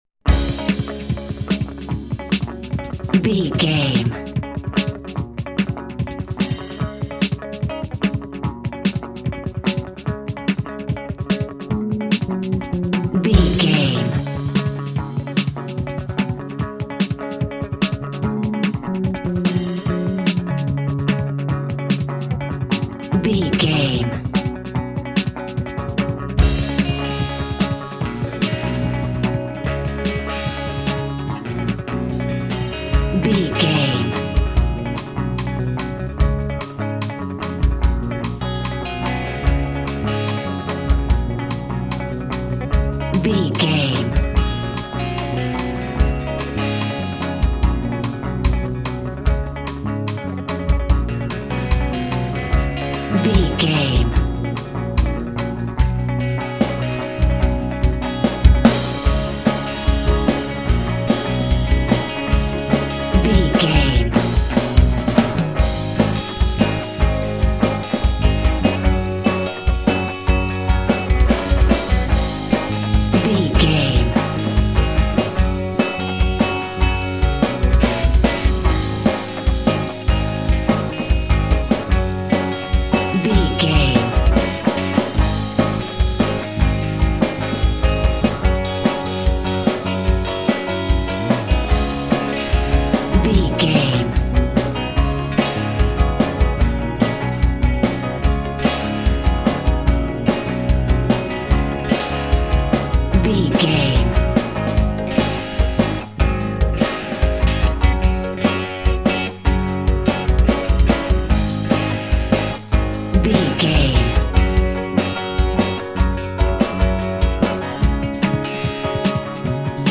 Epic / Action
Fast paced
Mixolydian
D♭
Fast
blues rock
distortion
rock and roll
pop rock music
drums
bass guitar
backing vocals
electric guitar
piano
hammond organ